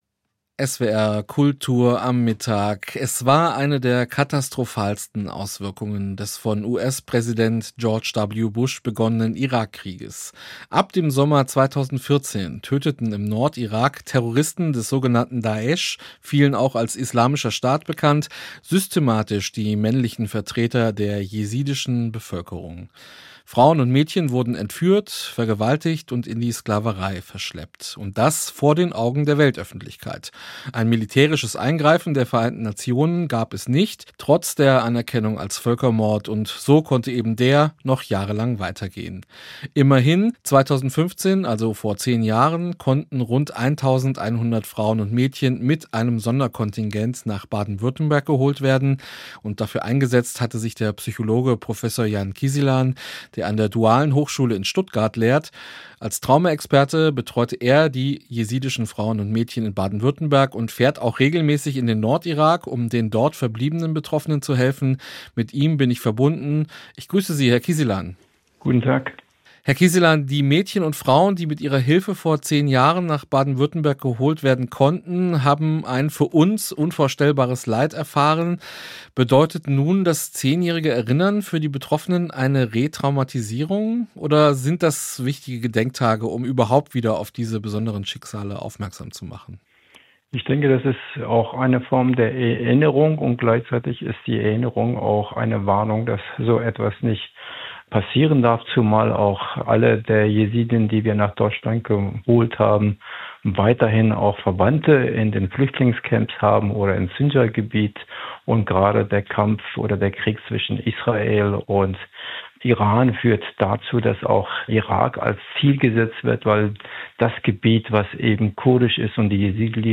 Gespräch
Interview mit